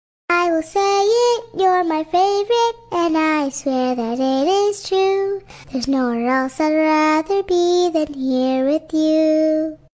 This little kitten has something special to sing just for you: “I love you soooo much!”